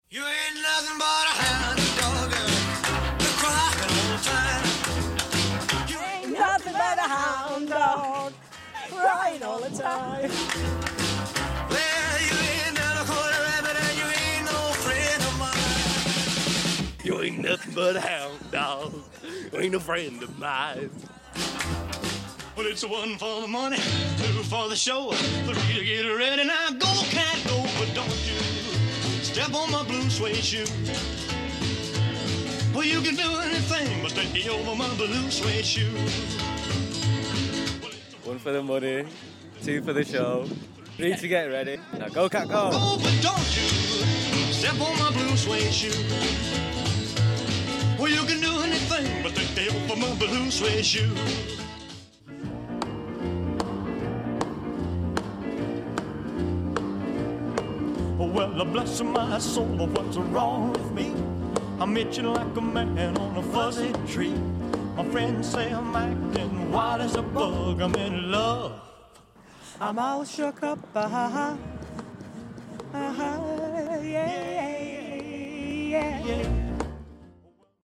People in Greater Manchester sing their favourite Elvis songs